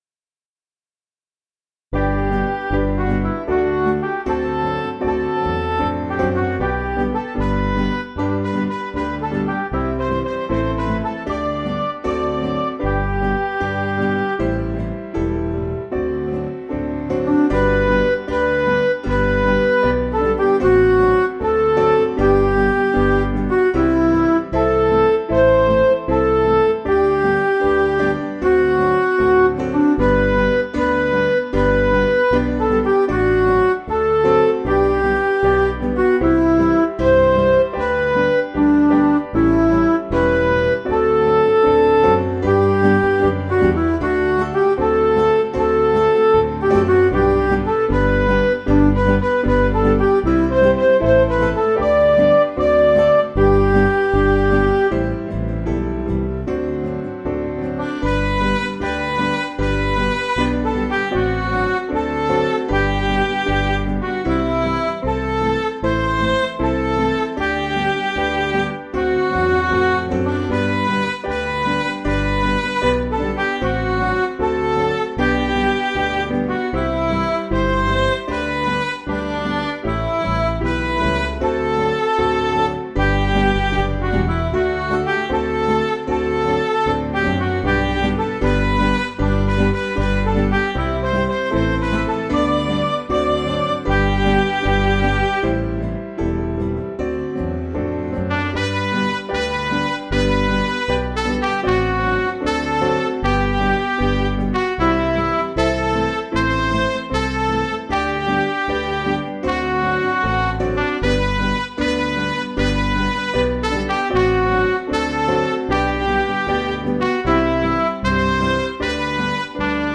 Piano & Instrumental
in 4/4 time   564.6kb